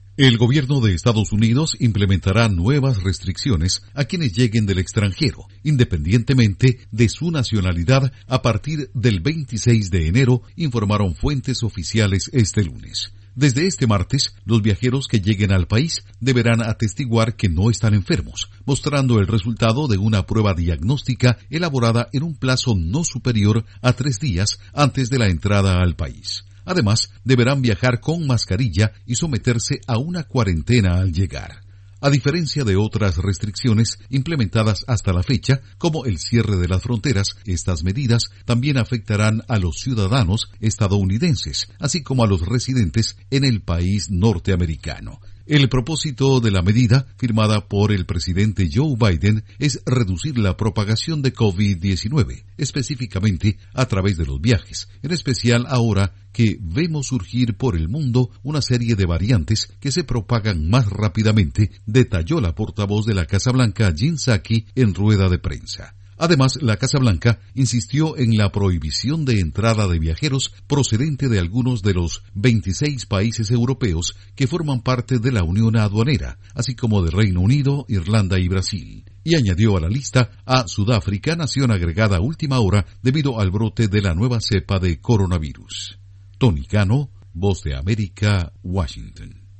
Informa desde la Voz de América en Washington